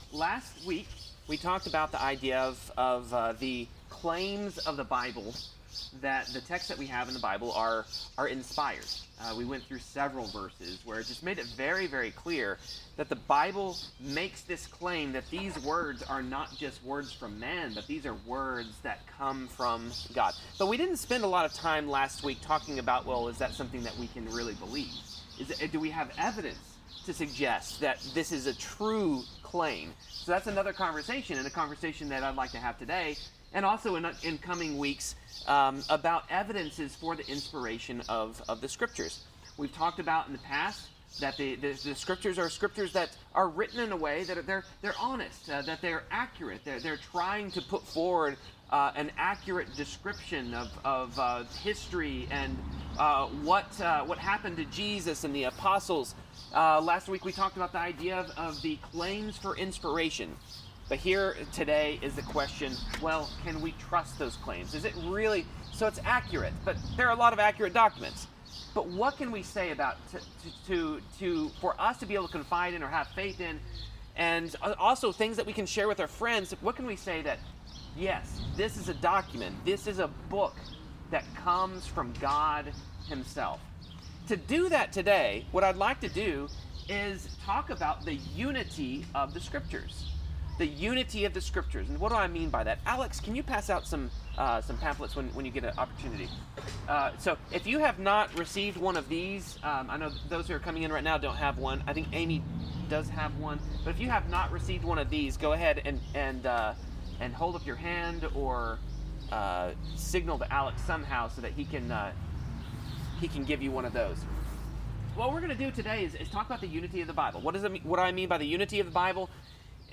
Trusting the Bible Service Type: Sermon The Bible is a big book with complicated stories and characters.